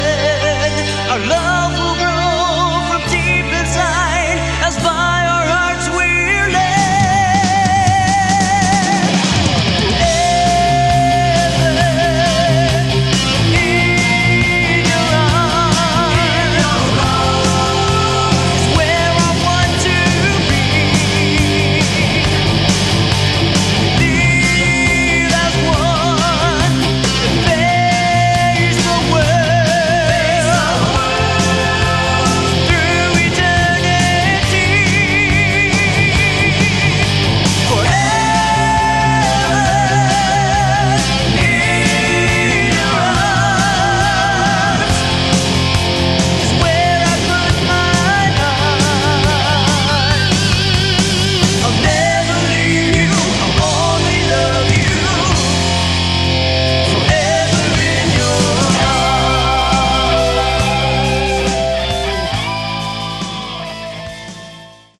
Category: Christian Hard Rock